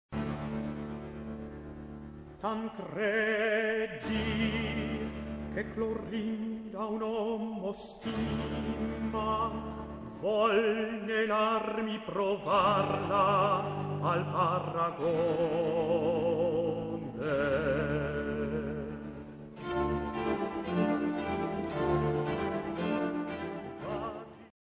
Testo zingt:
• De zangers werden begeleid door 5 strijkinstrumenten en een “basso continuo”.
Het werk wordt gezongen door 3 zangers: Tancredi (tenor), Clorinda (sopraan) en de verteller (bariton]), die het werk inleidt, de verhalende gedeeltes voor zijn rekening neemt en ook korte beschouwingen naar aanleiding van het gebeurde geeft.
Het stuk begint met een inleiding van de verteller-.